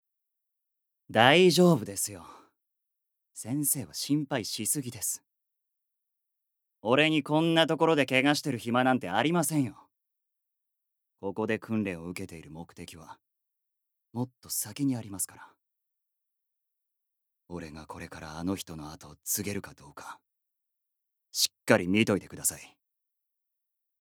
Voice Sample
セリフ１